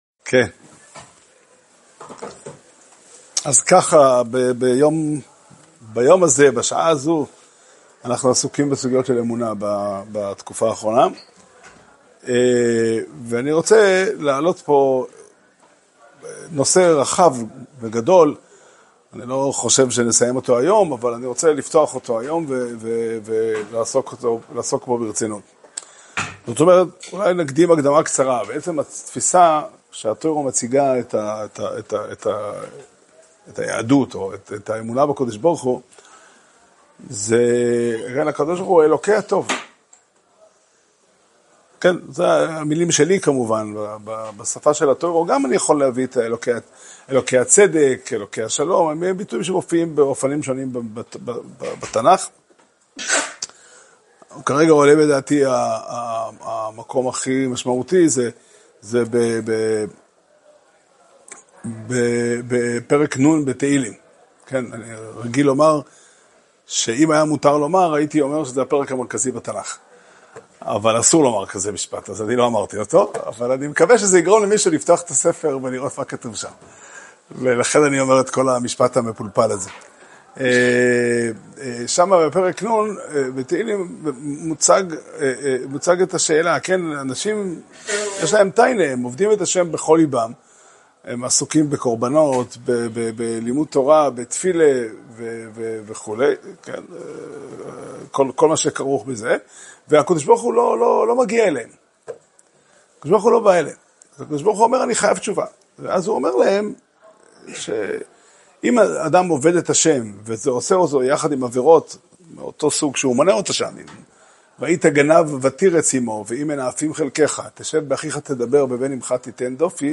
שיעור שנמסר בבית המדרש פתחי עולם בתאריך כ"ו חשוון תשפ"ה